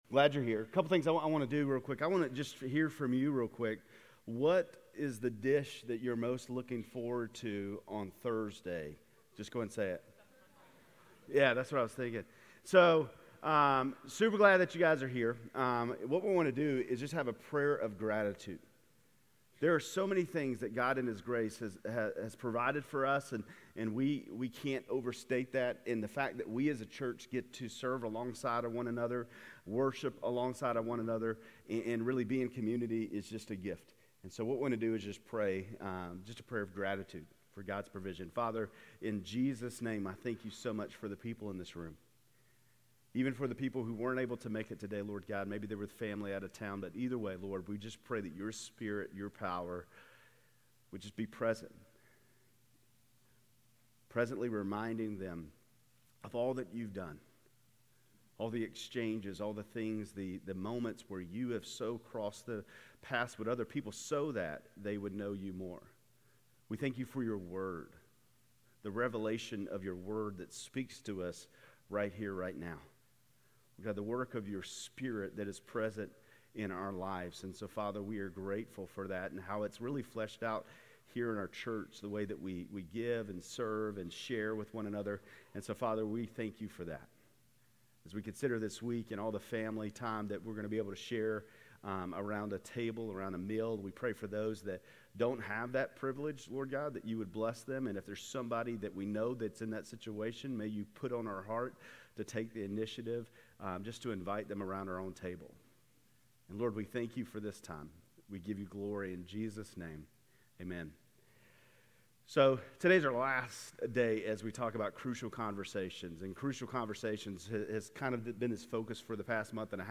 GCC-Lindale-November-19-Sermon.mp3